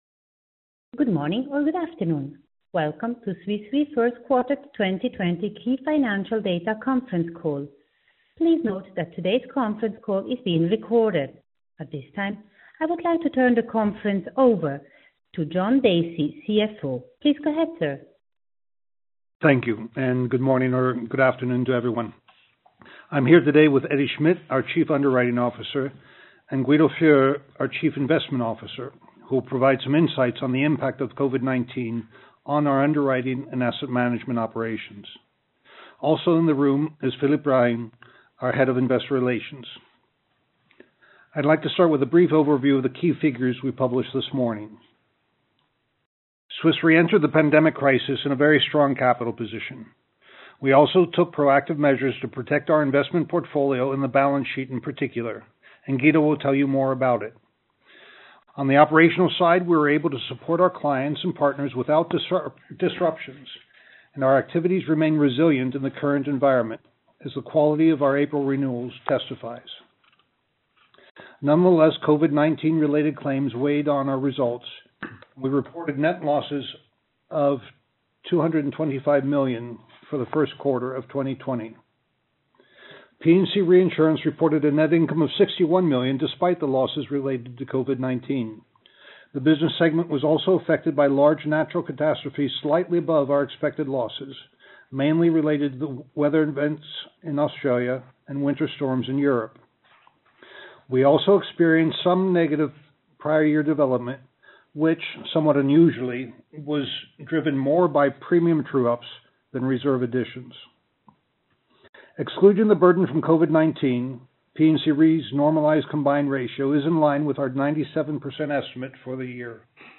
q1-2020-call-recording.mp3